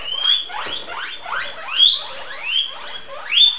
wheep.wav